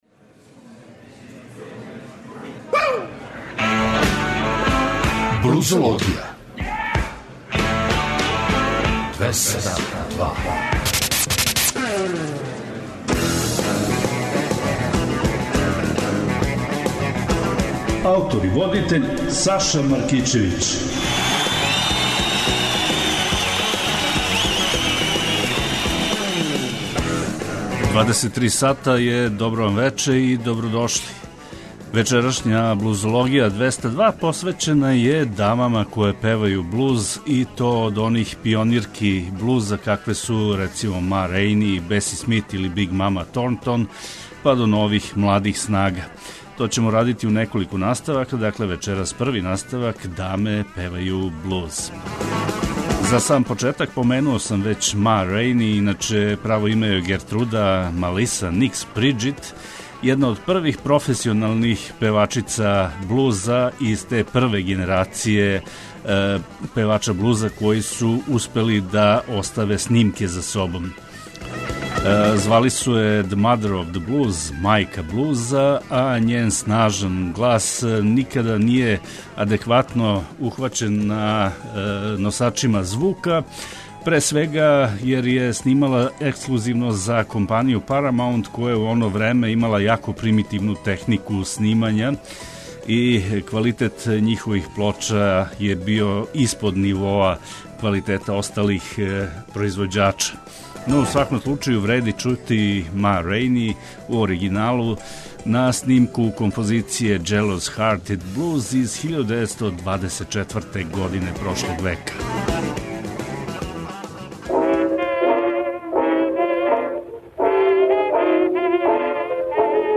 Поштујући захтеве многих слушалаца, Београд 202 је од 10. јануара 2015. покренуо нову музичку емисију под називом „Блузологија“.
Суботом од 23.00 до поноћи, нудимо вам избор нових музичких издања из овог жанра, али не заборављамо ни пионире који су својим радом допринели развоју блуза и инспирисали младе музичаре широм света да се заинтересују и определе за професионалну каријеру у овој области.